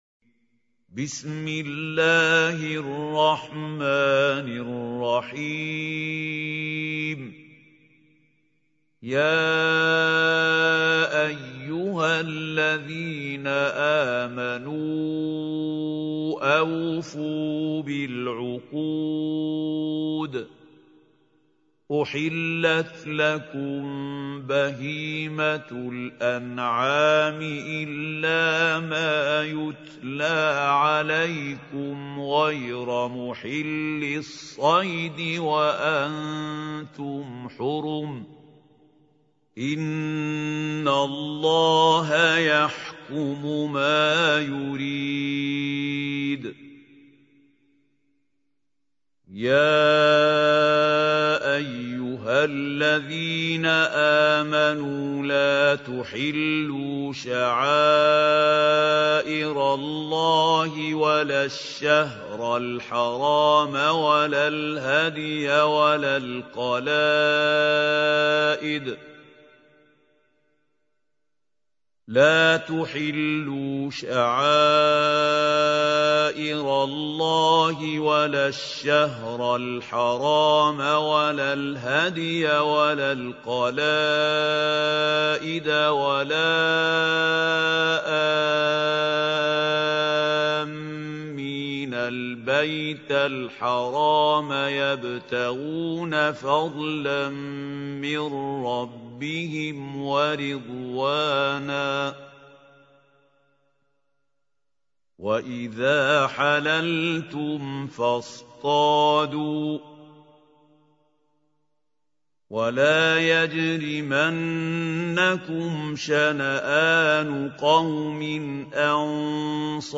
Listen online and download Tilawat of Surah Al-Maidah recited by Qari Mahmoud Khalil Al Hussary.